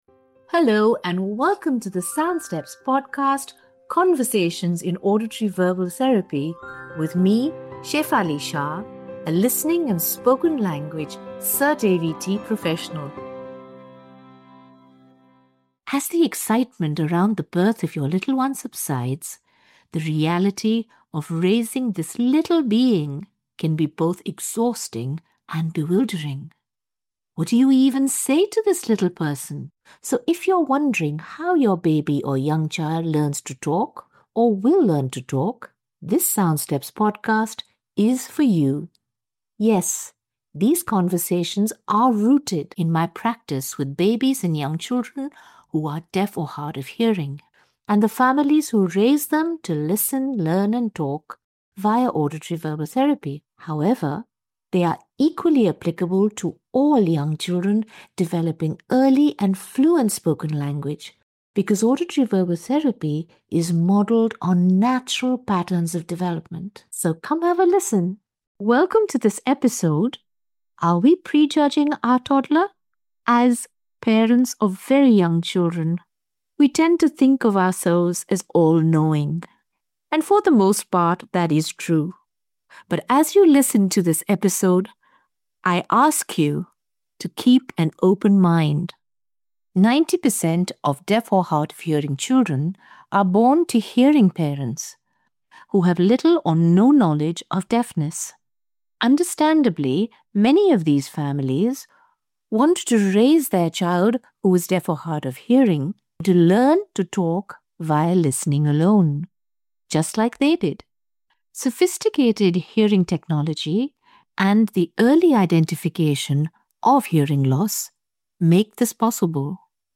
Conversations in Auditory-Verbal Therapy